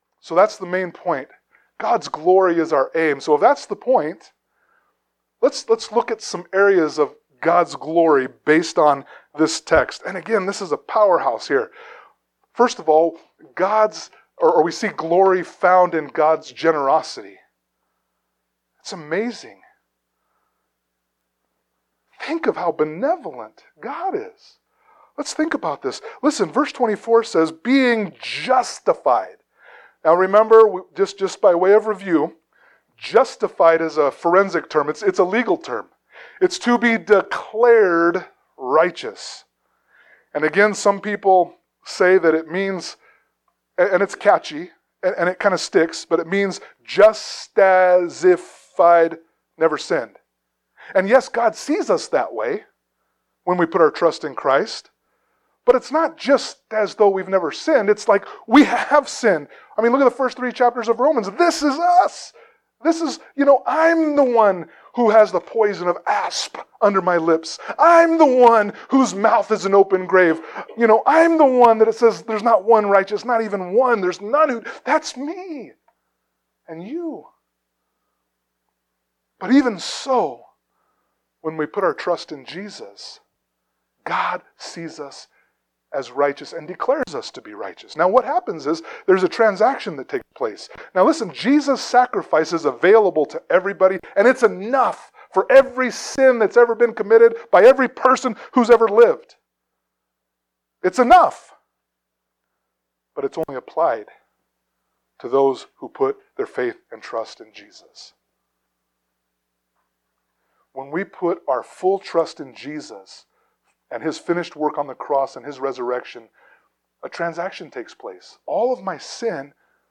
Note: Due to an unfortunate error, the first several minutes of this sermon were not recorded.